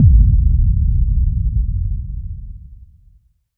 VEC3 FX Reverbkicks 07.wav